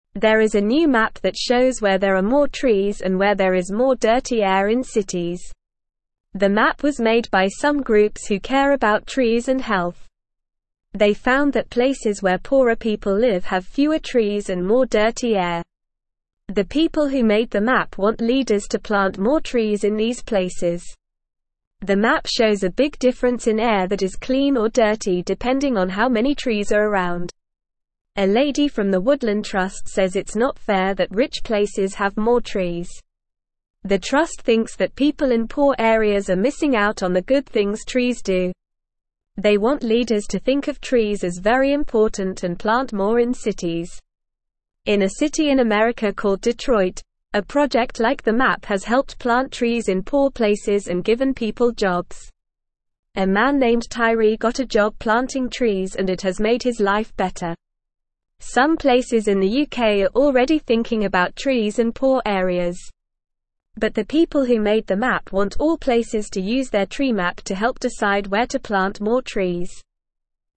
Normal
English-Newsroom-Lower-Intermediate-NORMAL-Reading-Map-Shows-Places-with-Few-Trees-Have-Dirty-Air.mp3